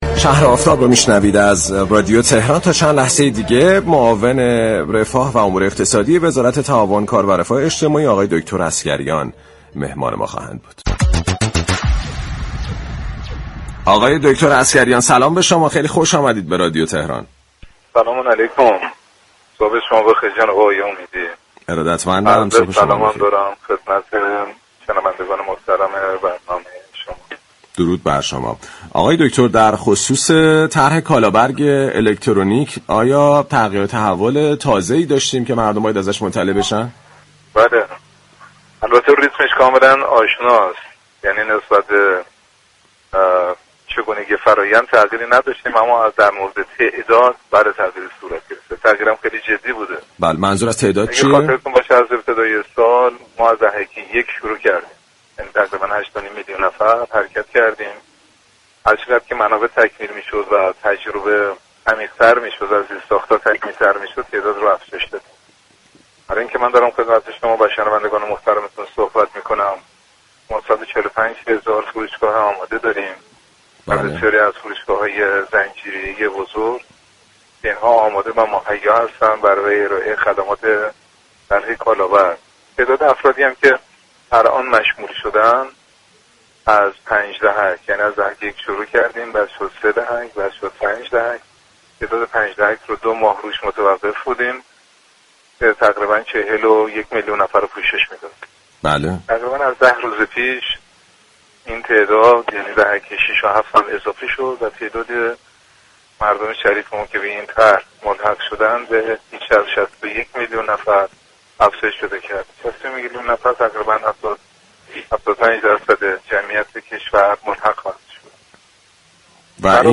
به گزارش پایگاه اطلاع رسانی رادیو تهران، علیرضا عسگریان معاون رفاه و امور اقتصادی وزارت تعاون، كار و رفاه اجتماعی در گفت و گو با «شهر آفتاب» اظهار داشت: فرآیند تخصیص یارانه تغییری نداشته ولی تعداد دریافت كنندگان یارانه افزایش پیدا كرده است.